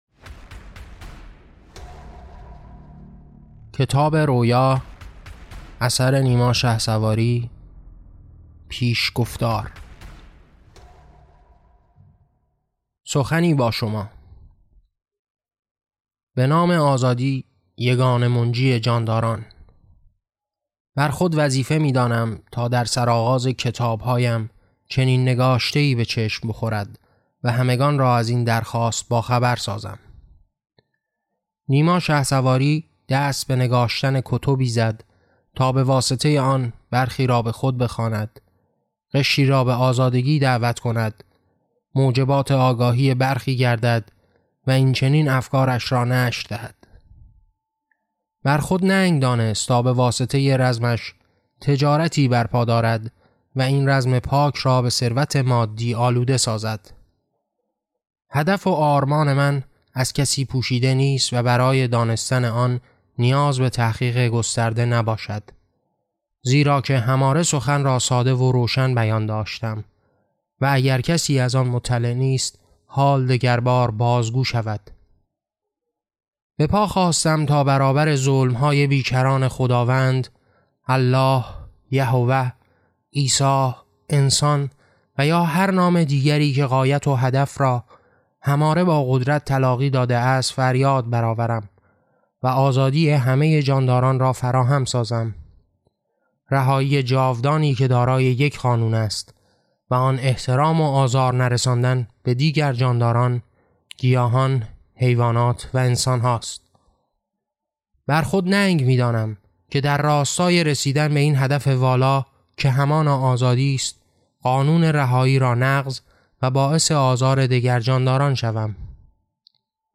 کتاب صوتی «رویا»
این نسخه شنیداری با کیفیت استودیویی جهت غوطه‌وری کامل در مفاهیم اثر تهیه شده است.